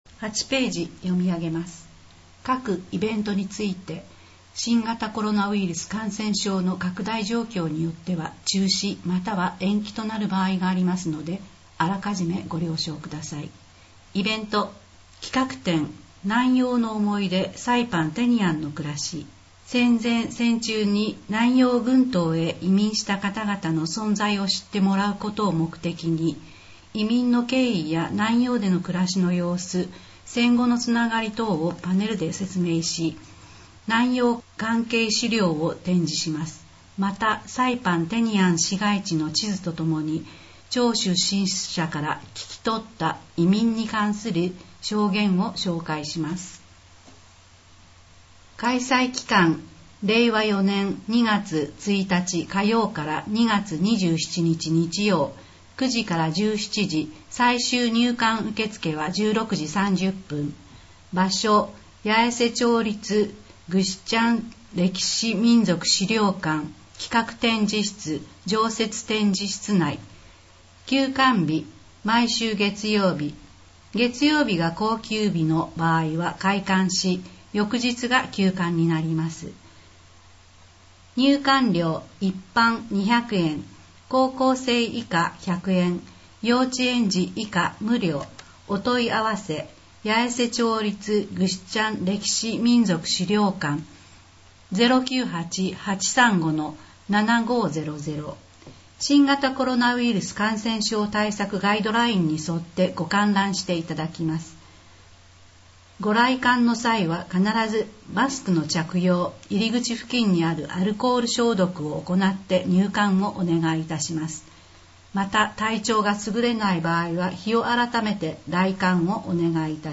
この音声は「音訳サークルやえせ」の皆さんのご協力で作成しています。